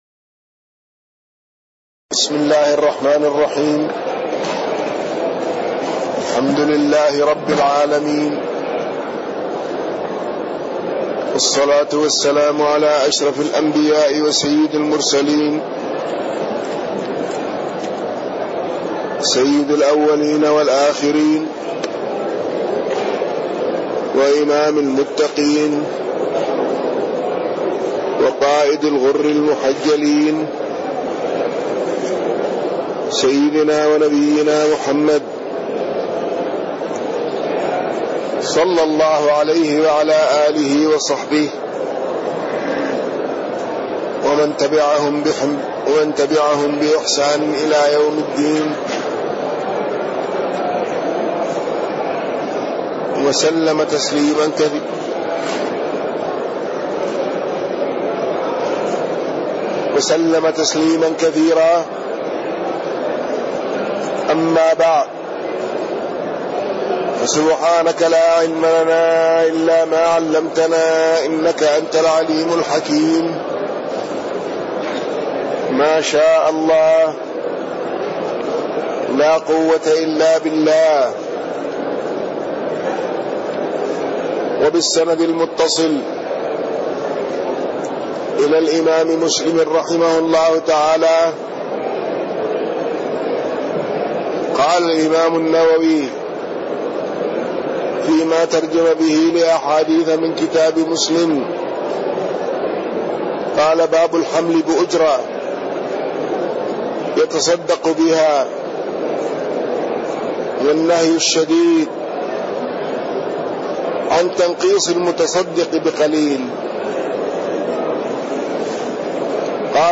تاريخ النشر ١٠ شعبان ١٤٣٢ هـ المكان: المسجد النبوي الشيخ